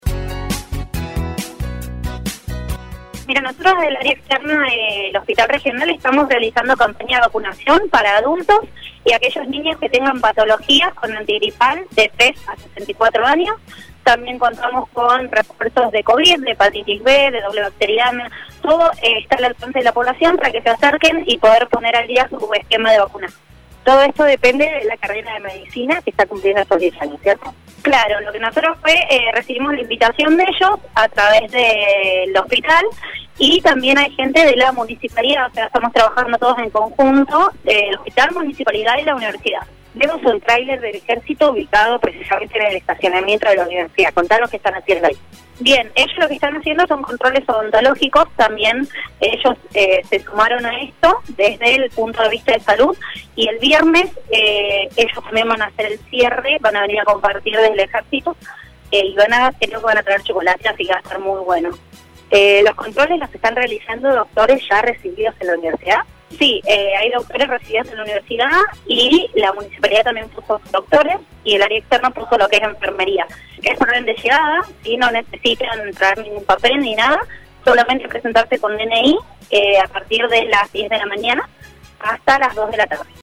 La palabra de una enfermera del Área programática en diálogo con LA MAÑANA DE HOY